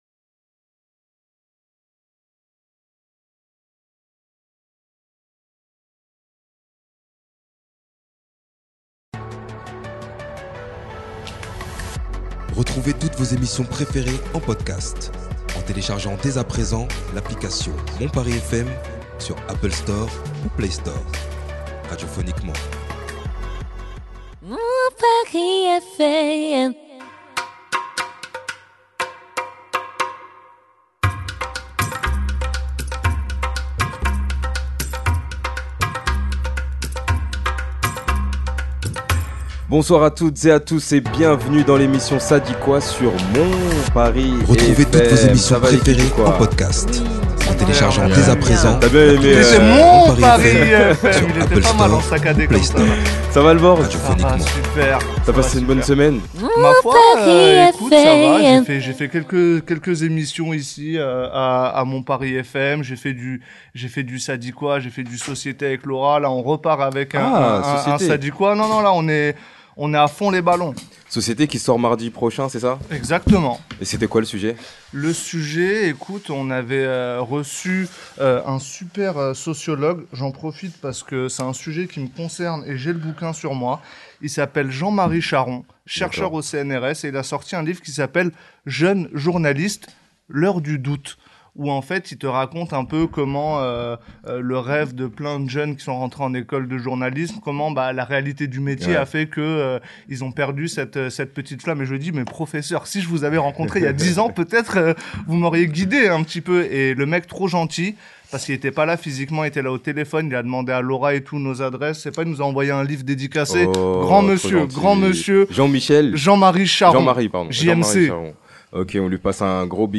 (Débat)